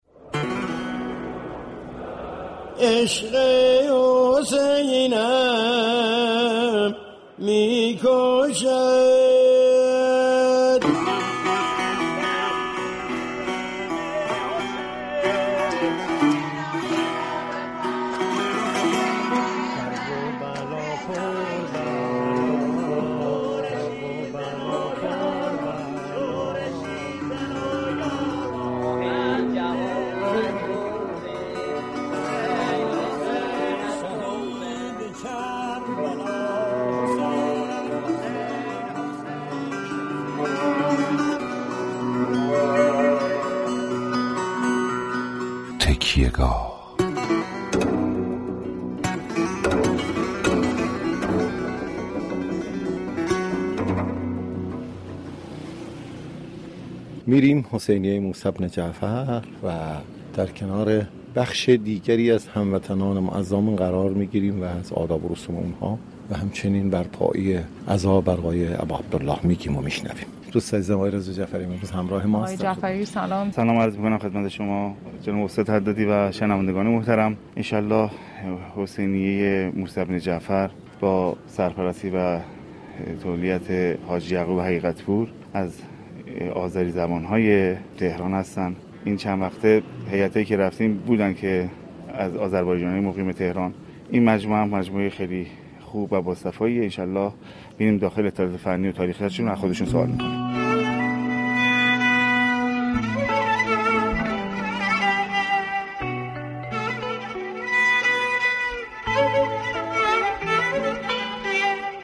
رادیو صبا در ویژه برنامه«تكیه گاه » با گزارش مستند به معرفی قدیمی ترین حسینیه ها و تكیه های عزاداری تهران می پردازد.